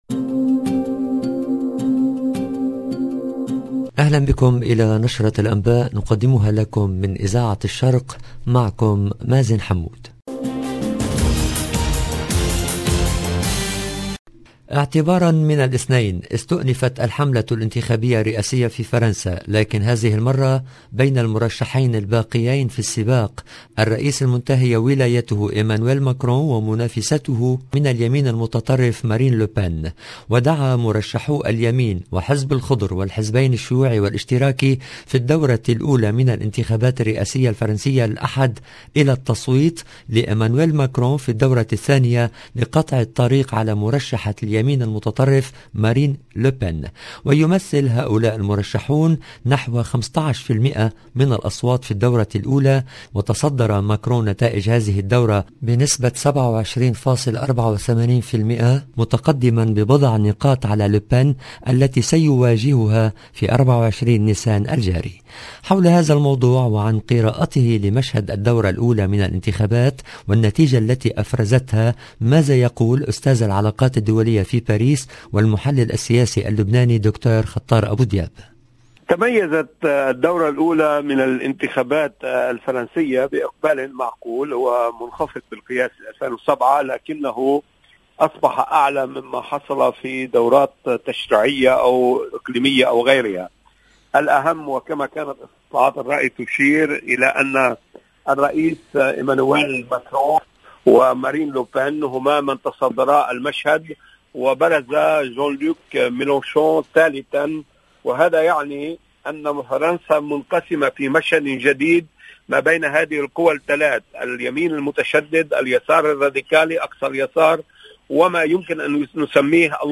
LB JOURNAL EN LANGUE ARABE
مقابلة مع استاذ العلاقات الدولية